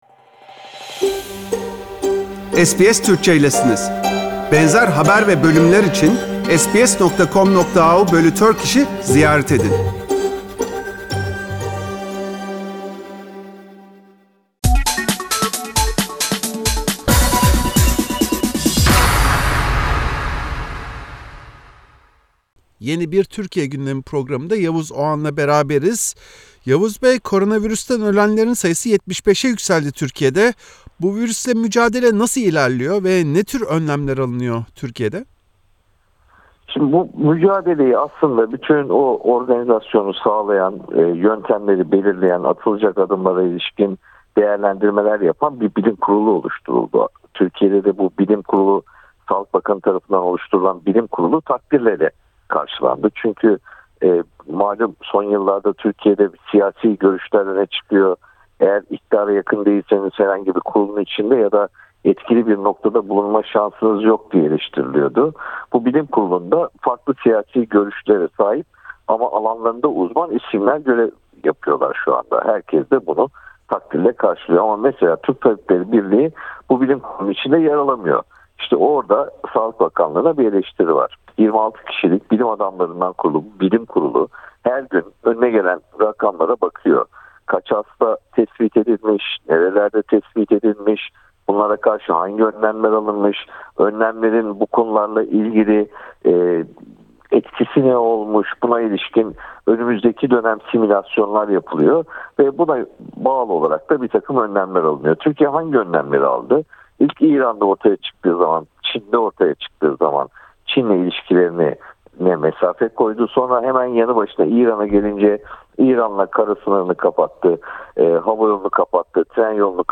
Gazeteci Yavuz Oğhan, Türkiye'de alınan önlemlere ve kapatılan yüzbinlerce işyeri ve okula rağmen COVID-19 hasta sayısının sürekli arttığını ancak hükümetin henüz sokağa çıkma yasağına soğuk baktığını söyledi.